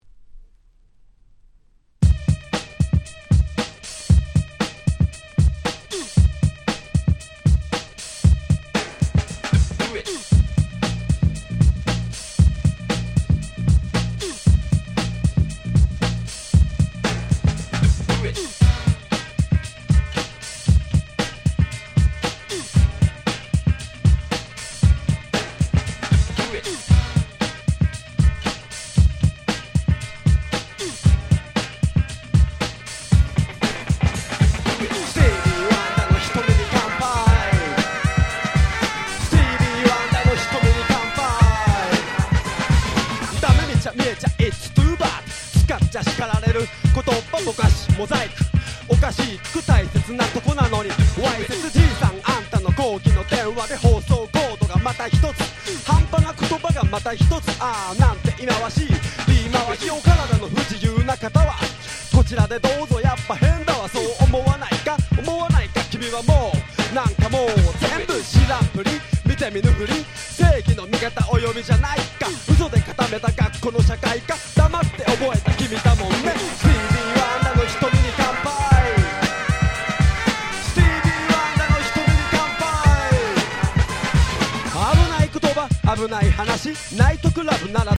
90' Japanese Hip Hop Nice Compilation !!